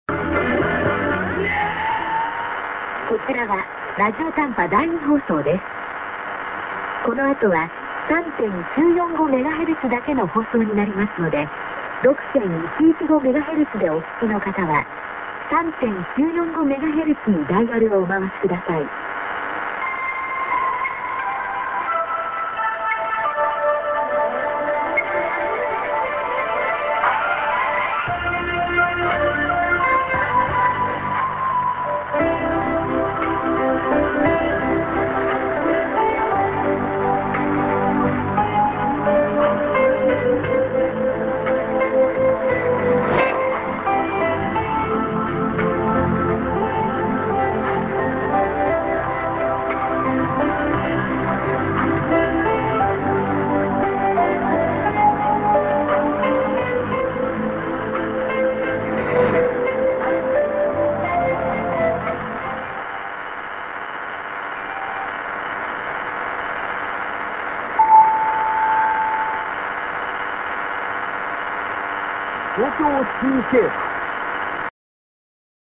End ->ID(women)-> -->after 3945kHz